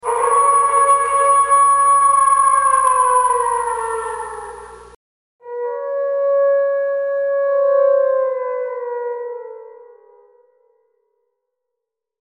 همچنین با استفاده از برنامه “Cakewalk” (ککواک) (۱۶) و سمپل های تحت برنامه “Kontakt” (کنتاکت) (۱۷) و بر اساس اطلاعات آنالیز، زوزه ی گرگ شبیه سازی گردید که در اینجا
shabihsazi.mp3